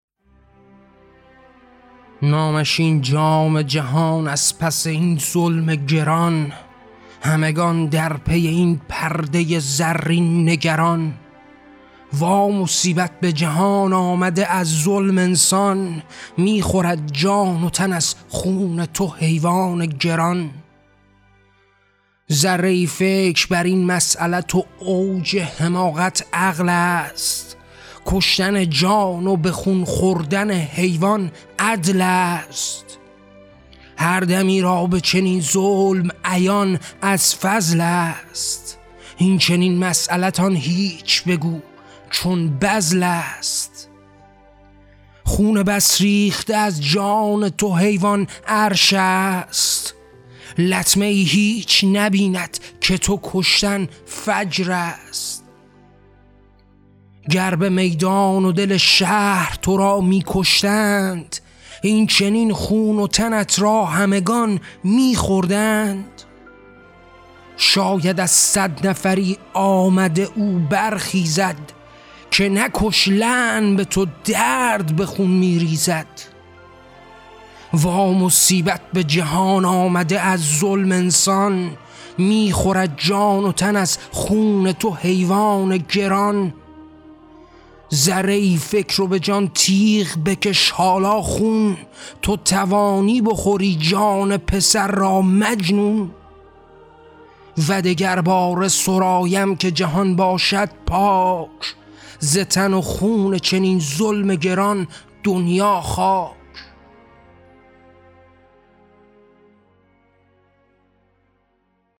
کتاب طغیان؛ شعرهای صوتی؛ نکش: واکاوی اخلاقی حقوق حیوانات و حرمت جان